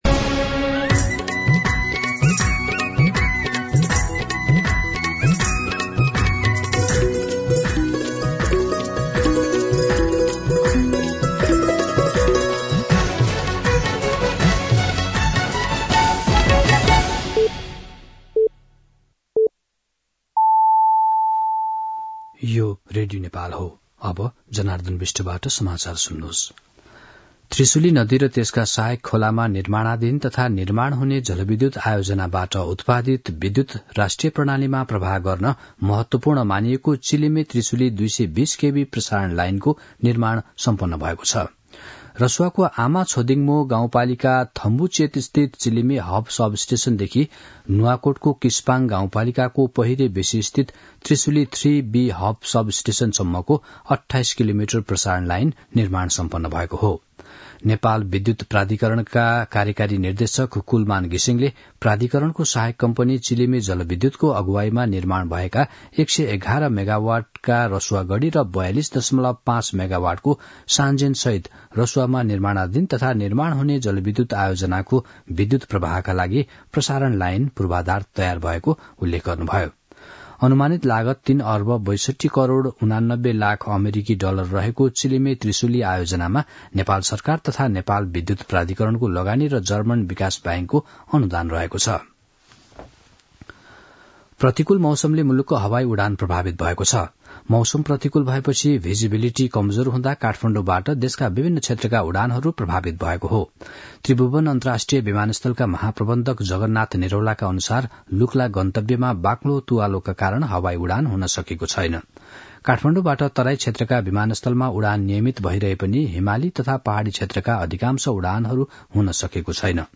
दिउँसो १ बजेको नेपाली समाचार : २० कार्तिक , २०८१
1-pm-Nepali-News-19.mp3